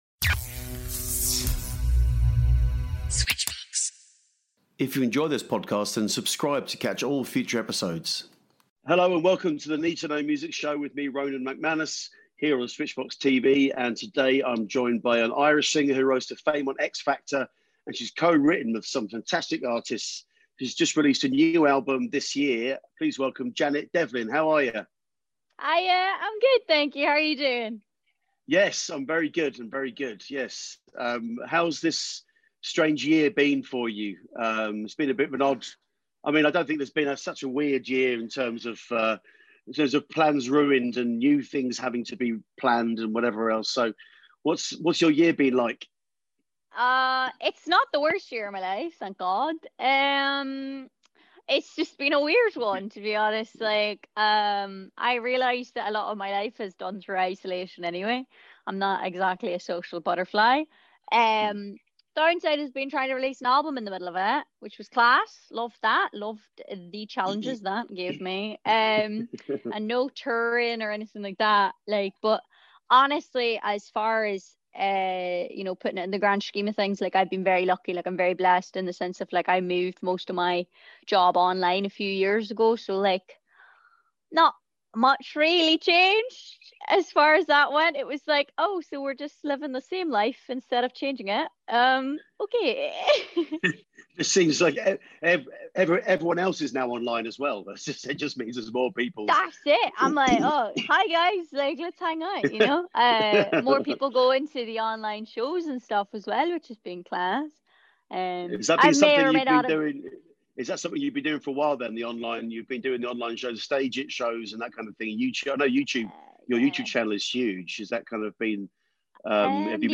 Season 1, Episode 19, Apr 14, 2021, 04:00 AM Headliner Embed Embed code See more options Share Facebook X Subscribe Irish former X Factor contestant opens up about her struggles with, and road back from, addiction, mental health issues and suicide attempts.